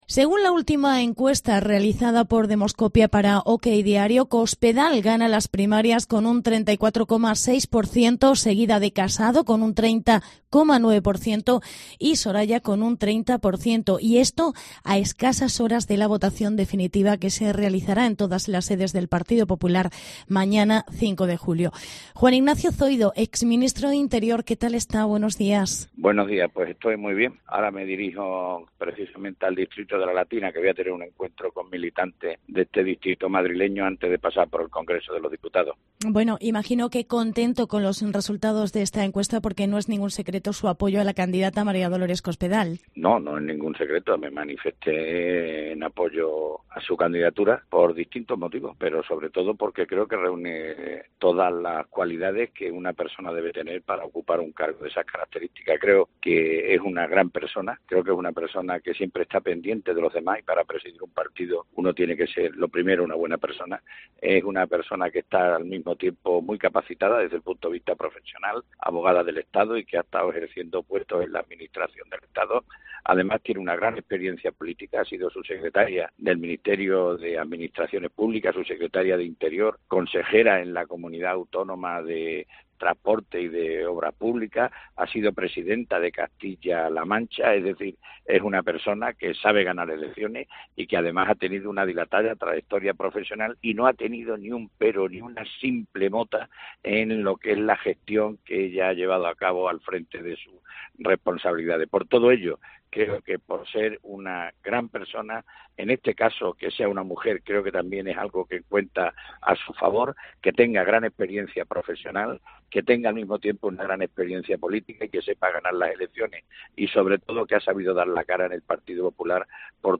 Hablamos con Zoido a unas horas de la votación de candidatos